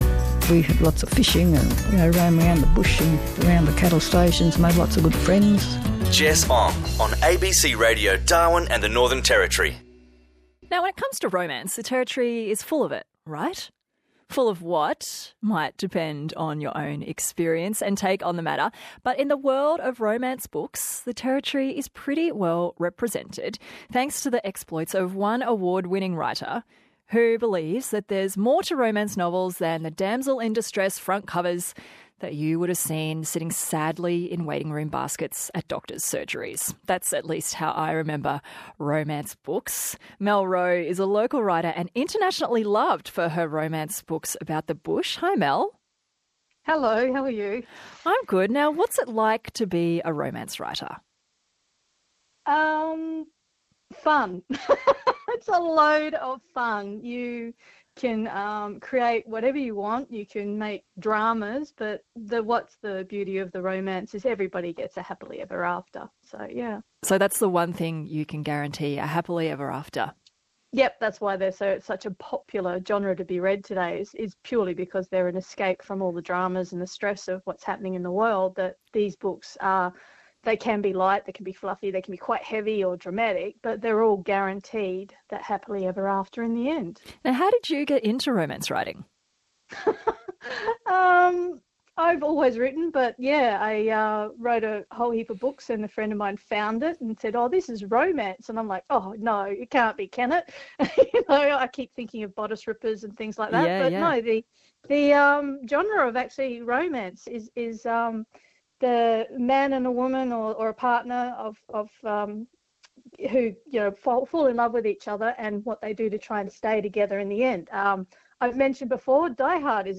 When this introvert, who is normally quite happy typing away all day does a rare radio interview.